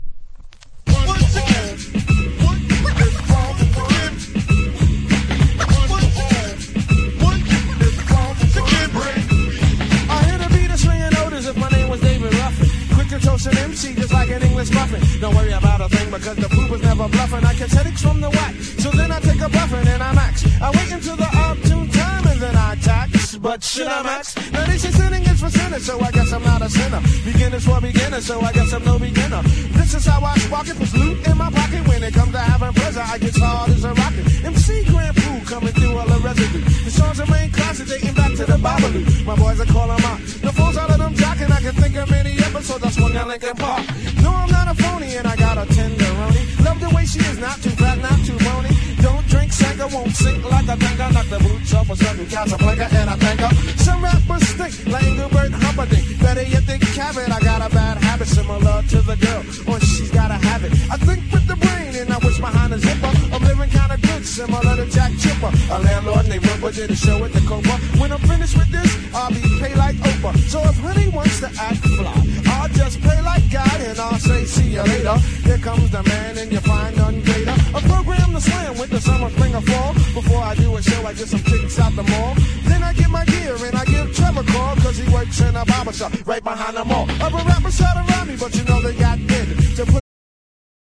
90’S HIPHOP